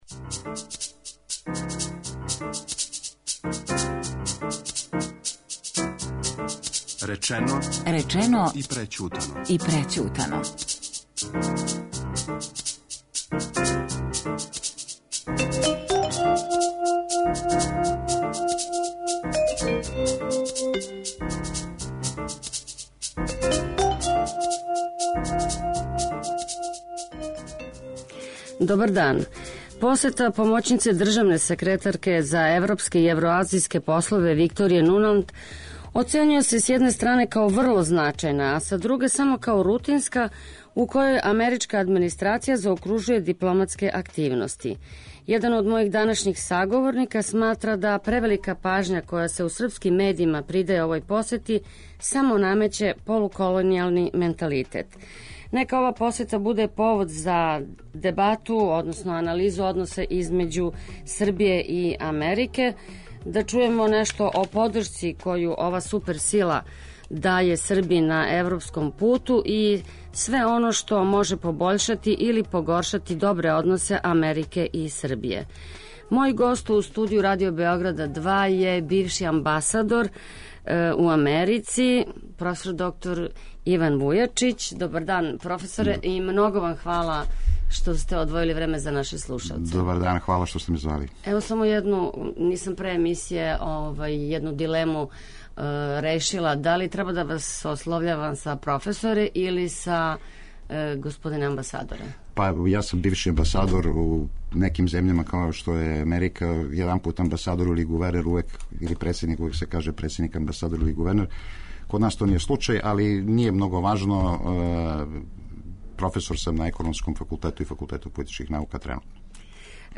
То је повод за дебату о односима Србије и САД